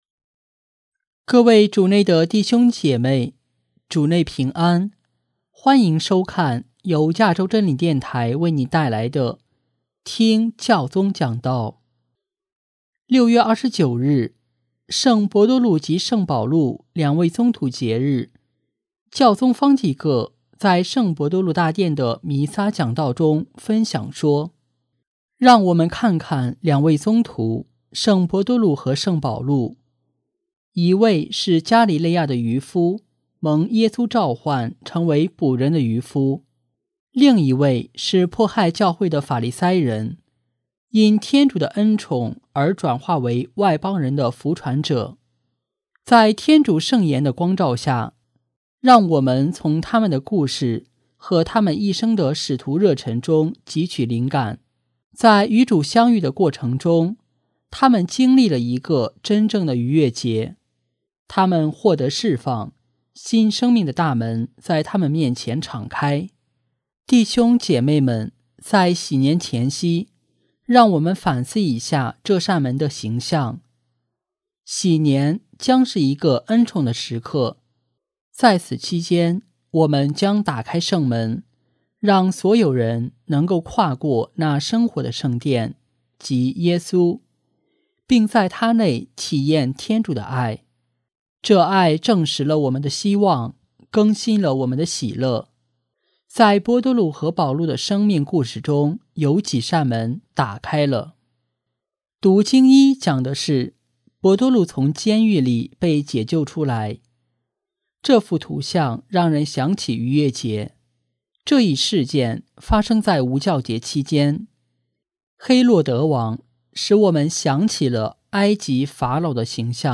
【听教宗讲道】|建设一个“敞开大门”的教会和社会
6月29日，圣伯多禄及圣保禄两位宗徒节日，教宗方济各在圣伯多禄大殿的弥撒讲道中，分享说：